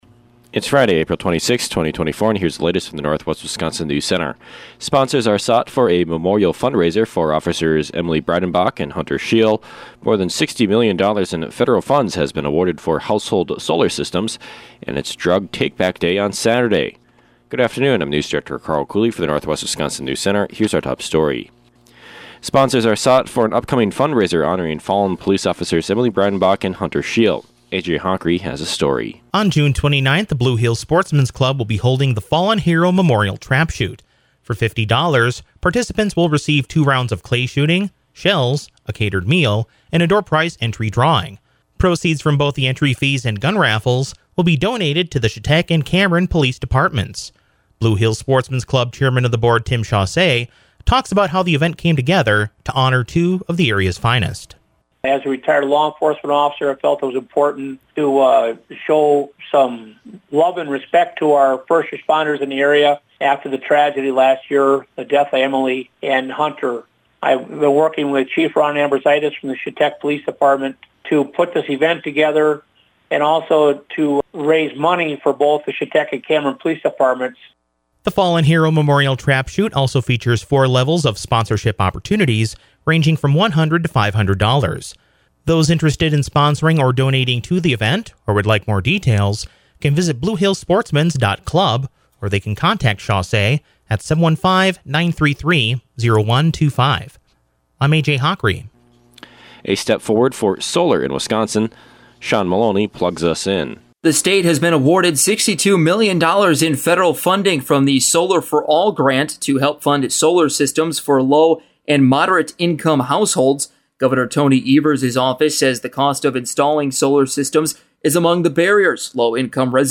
AM NEWSCAST – Friday, April 26, 2024 | Northwest Builders, Inc.